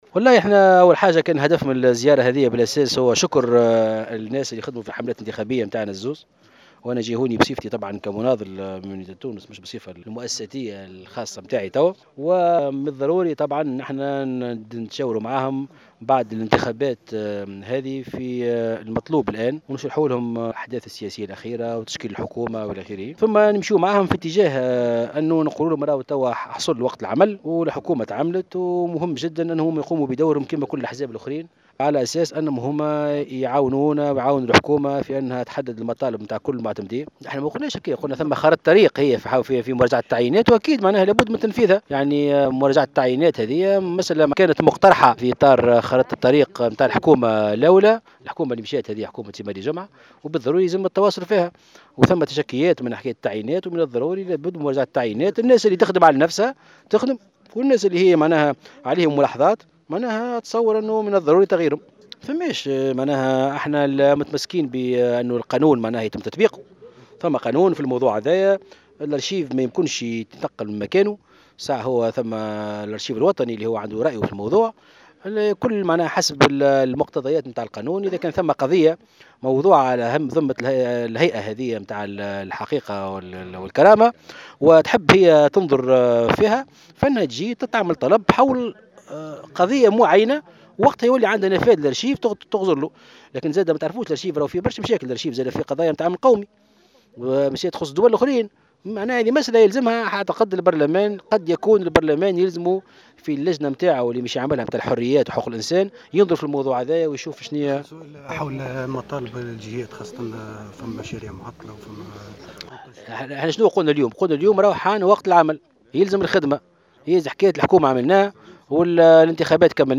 أما بخصوص مسألة الارشيف التي طرحت مؤخرا مع انطلاق عمل هيئة الحقيقة والكرامة، اعتبر مرزوق، في تصريح للجوهرة أف أم، أن من حق الهيئة النفاذ للارشيف بعد التقدم بطلب في خصوص قضية معينة وفق ما يقتضيه القانون مضيفا أن المسألة تحتاج لأن تعرض على أنظار مجلس نواب الشعب لفض الإشكال القائم.